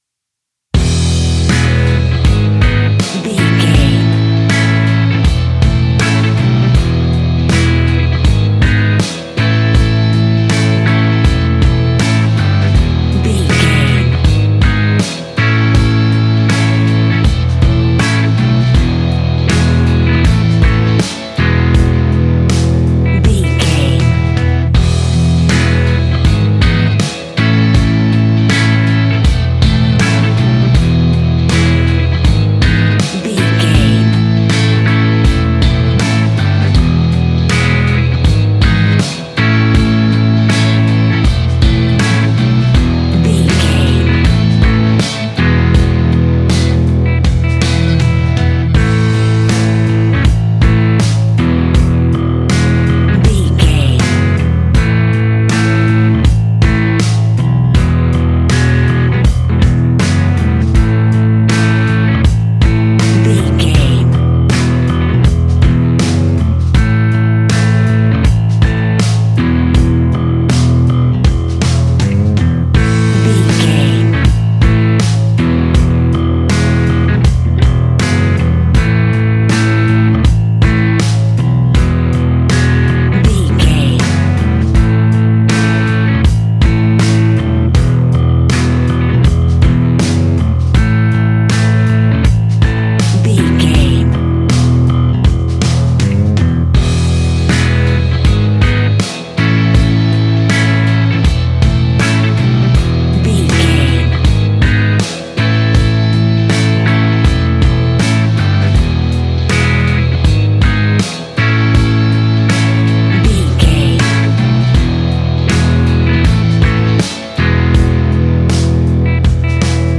Ionian/Major
indie pop
energetic
uplifting
upbeat
groovy
guitars
bass
drums
piano
organ